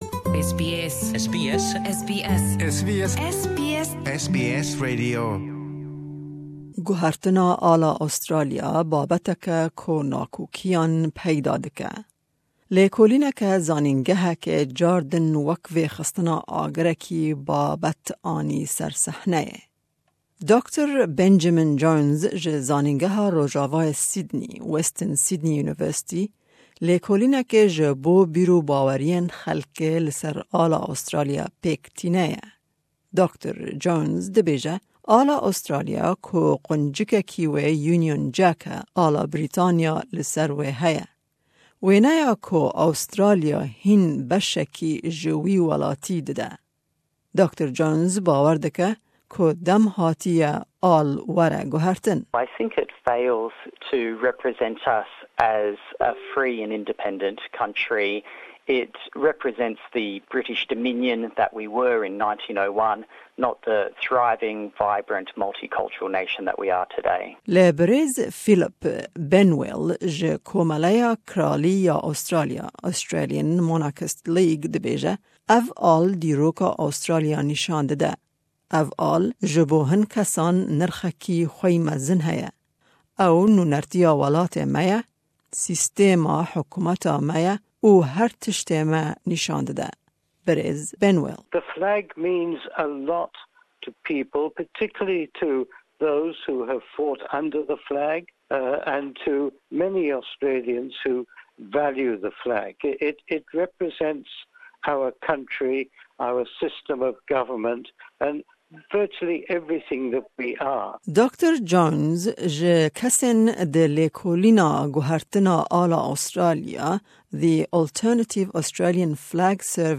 Di vê raporta SBS de nîqash li ser guhertina ala Australia dibe. Hin kes li dijin û hin kes jî dixwazin al were guhertin.